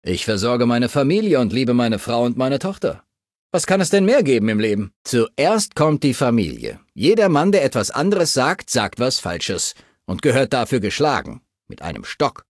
Datei:Maleadult01default dialoguean andalewhatdoyou 0001ec90.ogg
Fallout 3: Audiodialoge